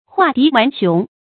成語注音ㄏㄨㄚˋ ㄉㄧˊ ㄨㄢˊ ㄒㄩㄥˊ
成語拼音huà dí wán xióng
畫荻丸熊發音